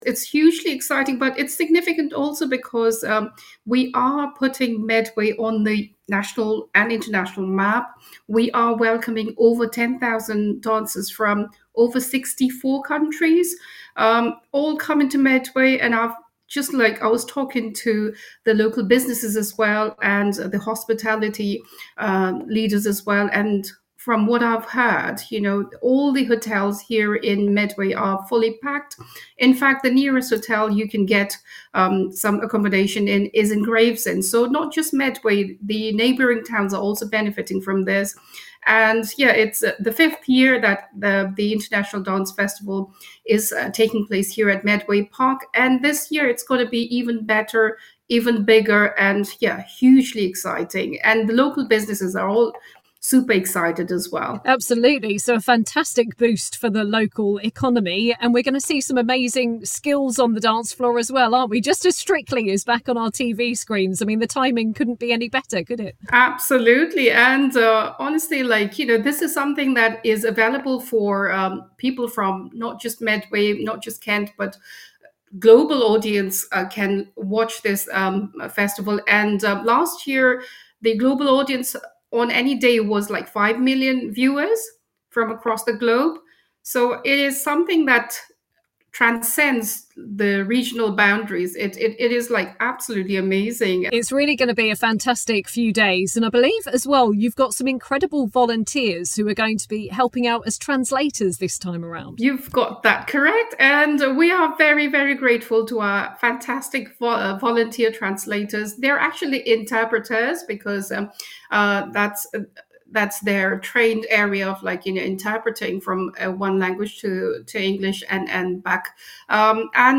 Listen: Medway councillor Nina Gurung chats about the 72nd International Dance Festival coming to Medway Park in Gillingham - 03/10/2025